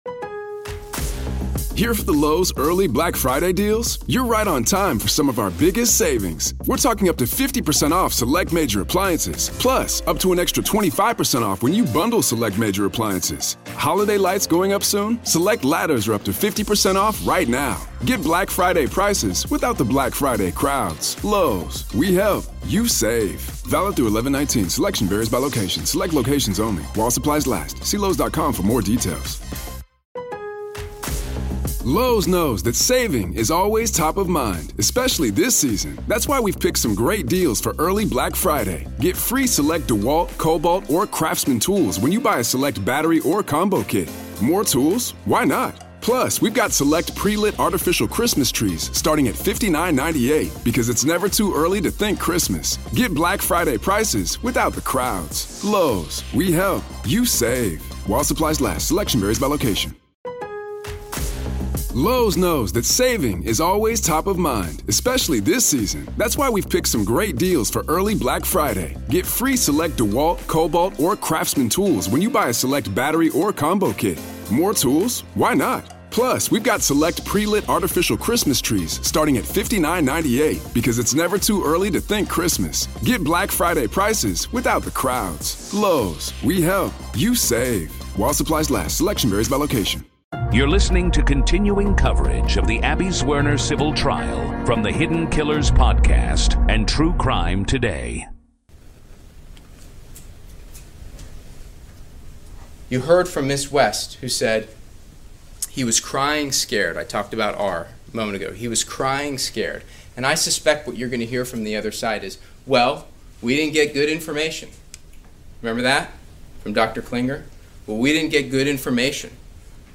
You’ll hear unfiltered courtroom audio, direct from the trial